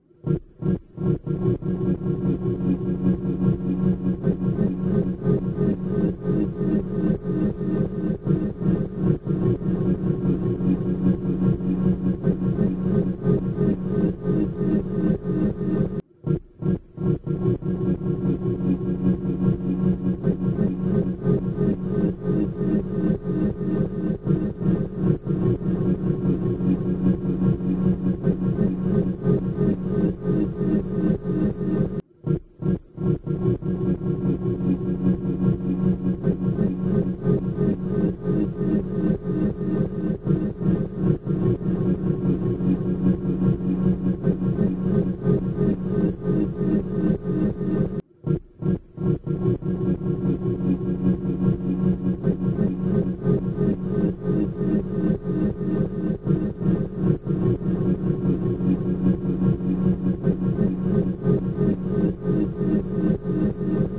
Speed 50%